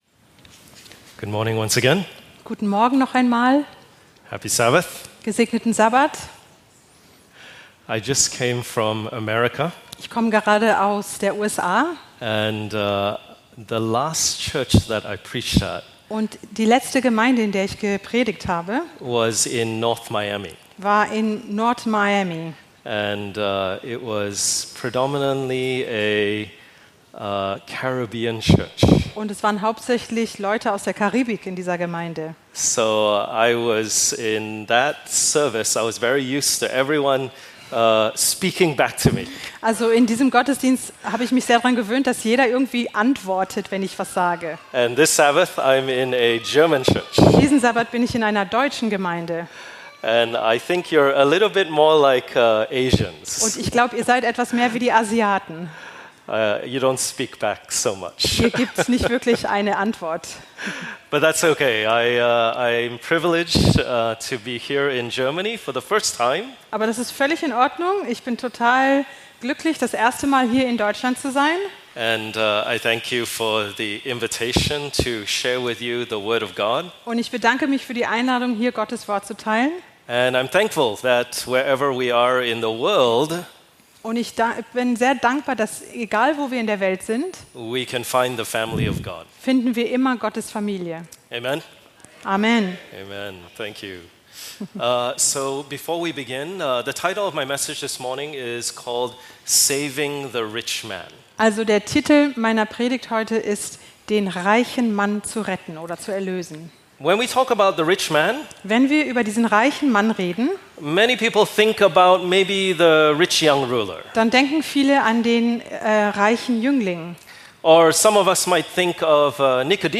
DasWort - Predigten Podcast